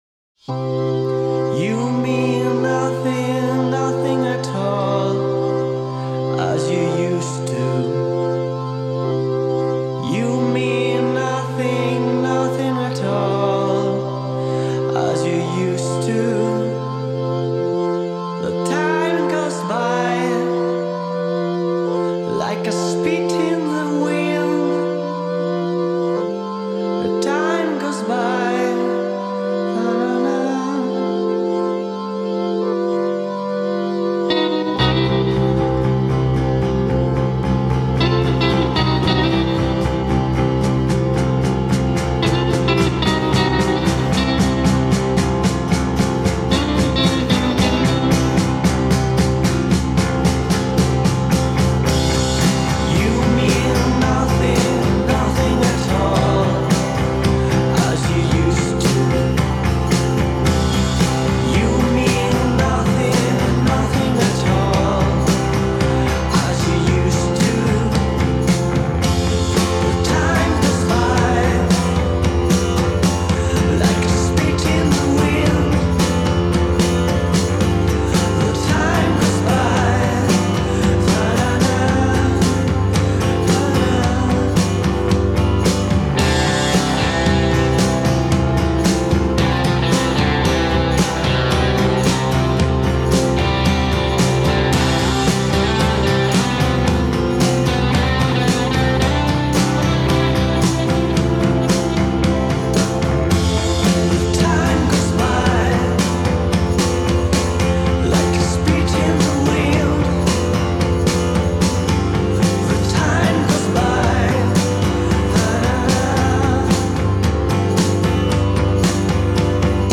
voce e chitarra
chitarra e cori